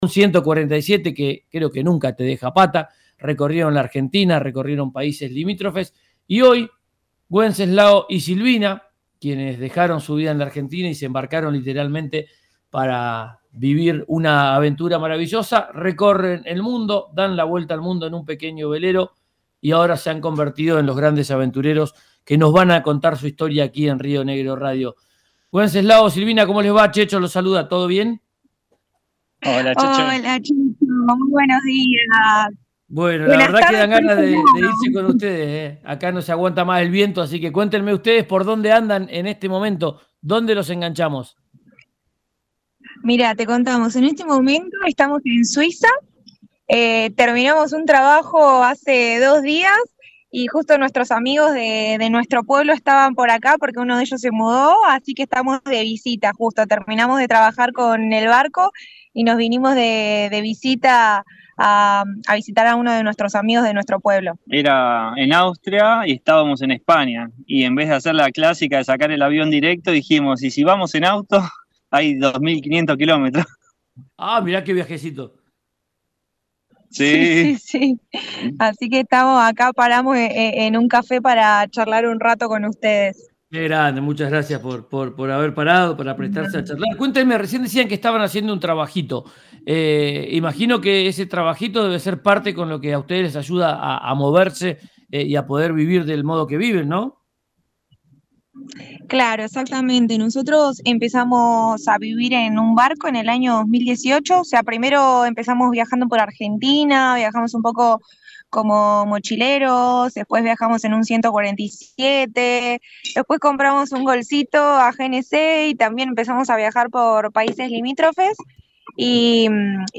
Ahora se embarcaron en otra travesía maravillosa: recorren el mundo en un pequeño velero. En diálogo con RÍO NEGRO RADIO contaron su historia de vivir de viaje a «Ya es tiempo».